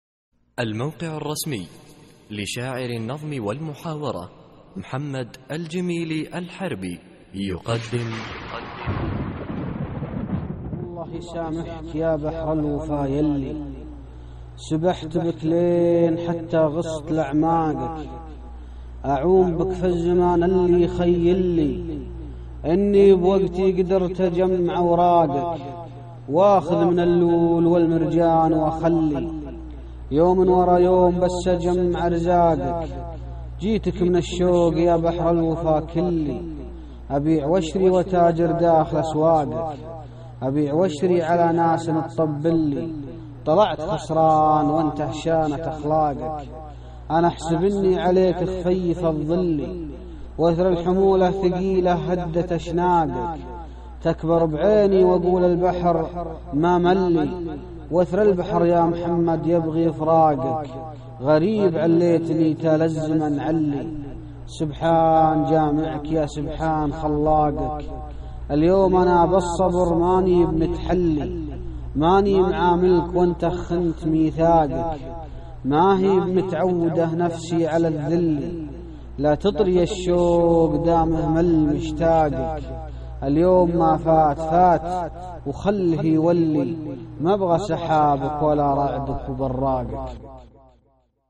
القصـائــد الصوتية